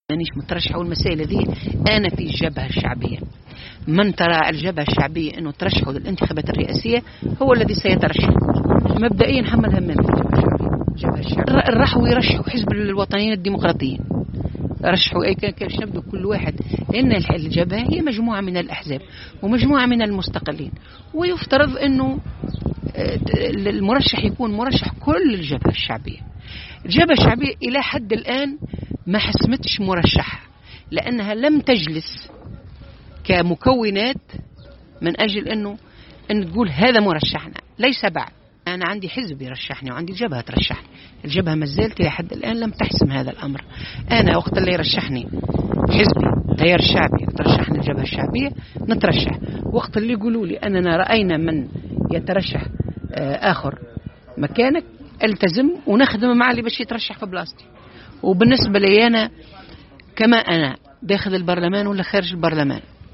على هامش حضورها أشغال المؤتمر الجهوي الاول للتيار الشعبي بمدينة مدنين